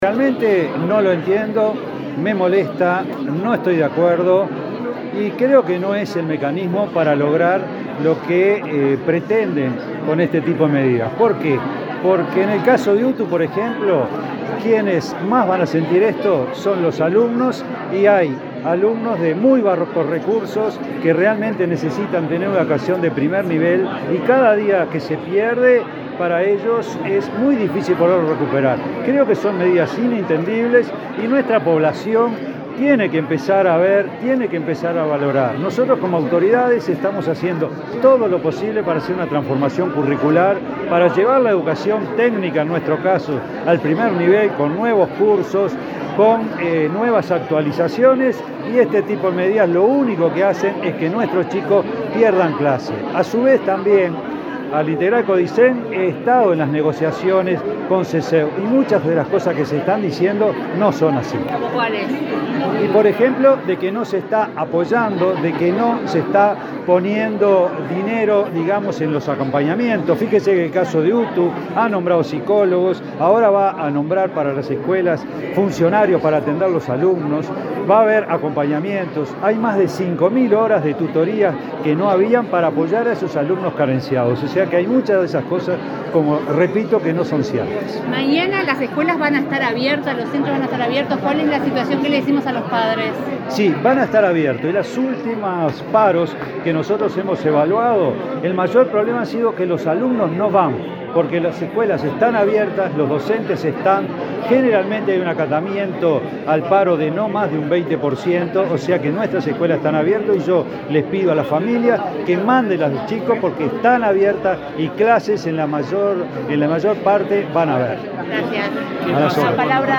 Declaraciones del director general de UTU
Declaraciones del director general de UTU 20/06/2023 Compartir Facebook X Copiar enlace WhatsApp LinkedIn El director general de la UTU, Juan Pereyra, participó este martes 20 en el Primer Encuentro de la Red de Oportunidades, instancia para generar herramientas a favor de la reinserción efectiva de personas egresadas del sistema penitenciario. Antes dialogó con la prensa.